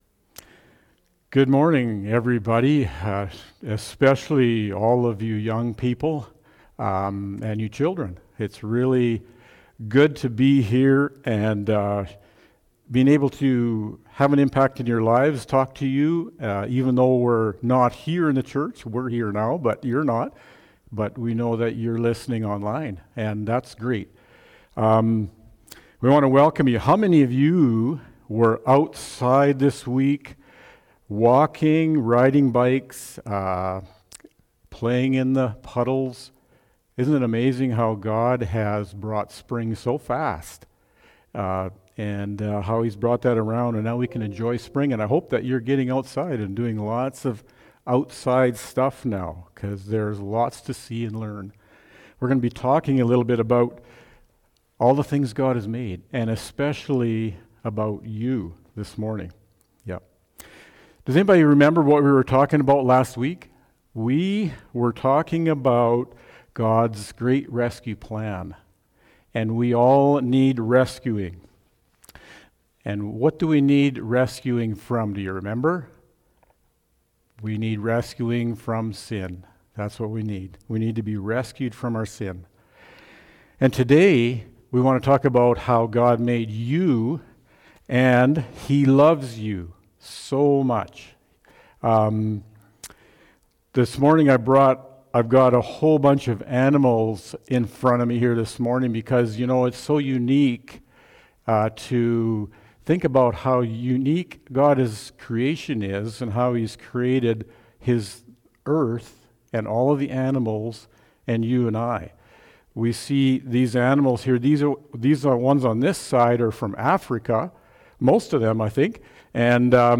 Children’s Message
Childrens-Story.mp3